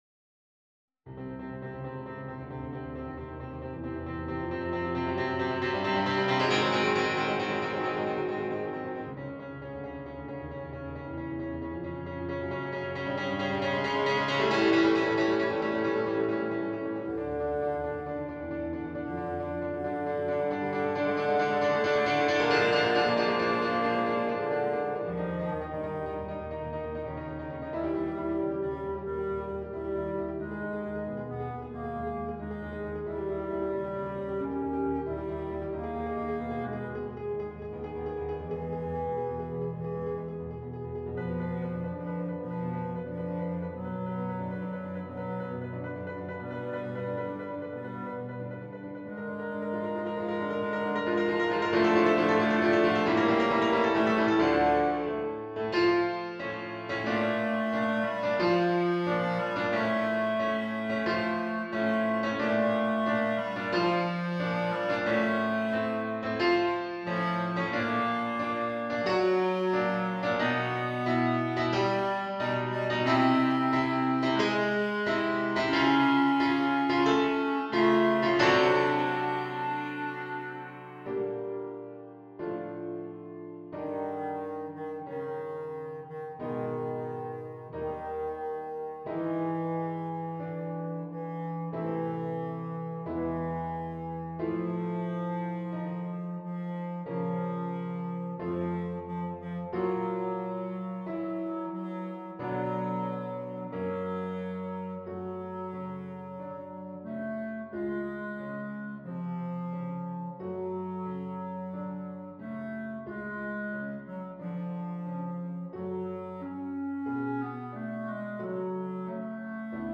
This file contains the performance, accompaniment, and sheet music for Bb Clarinet.